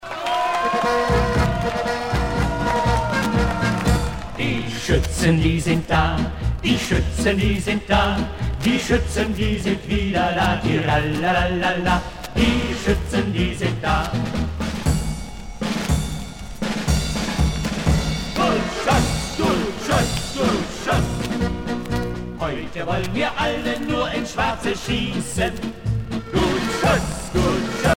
danse : marche
Pièce musicale éditée